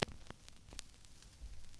crackle dust hiss noise pop record static turntable sound effect free sound royalty free Sound Effects